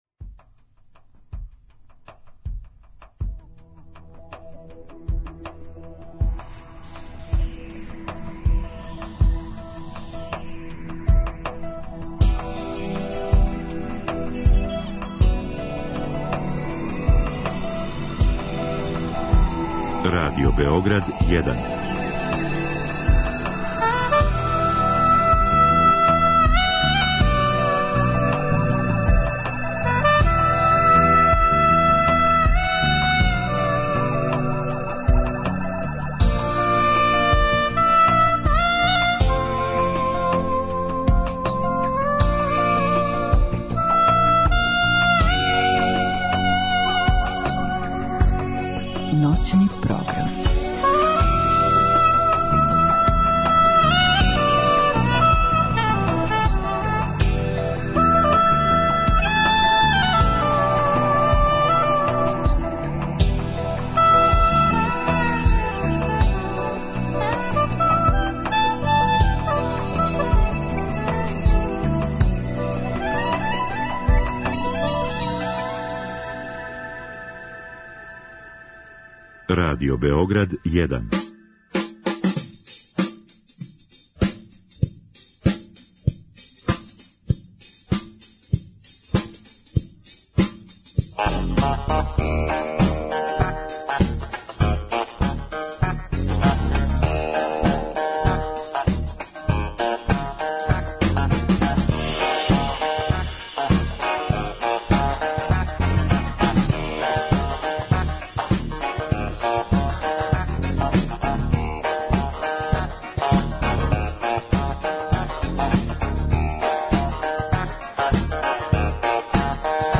Гости емисије биће Вране Камене, прави београдски гаражни рок бенд.